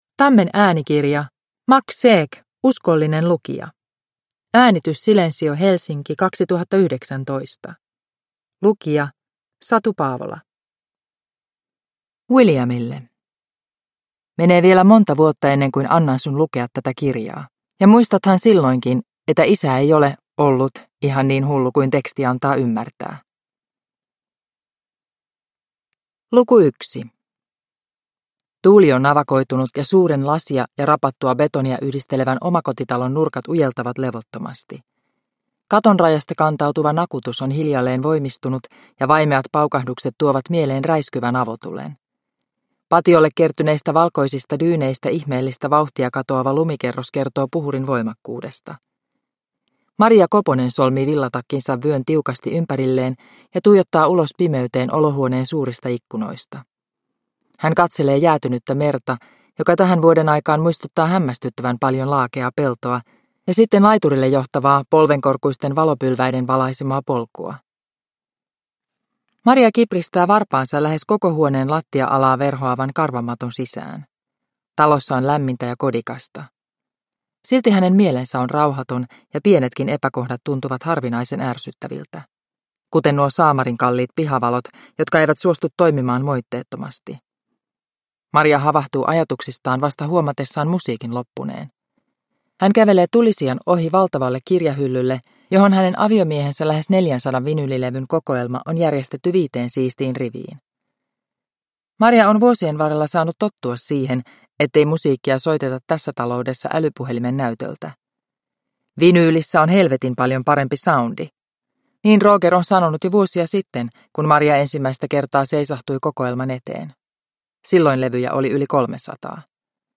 Uskollinen lukija – Ljudbok – Laddas ner